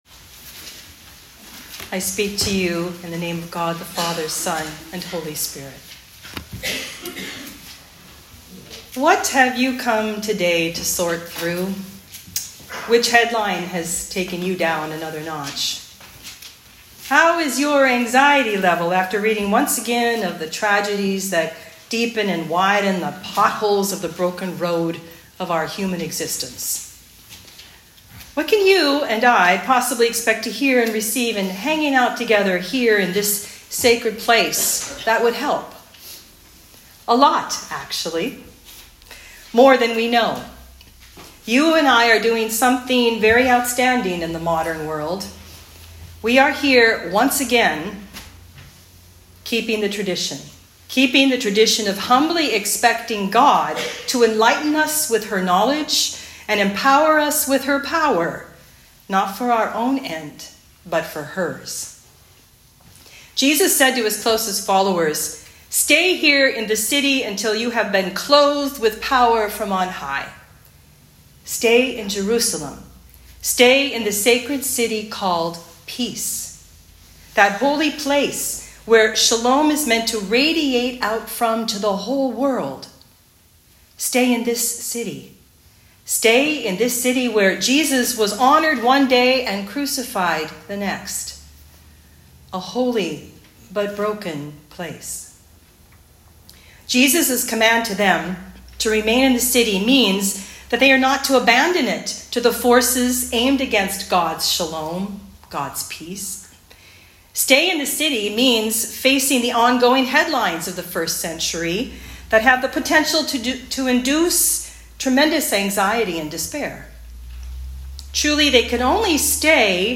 Ascension Sunday Talk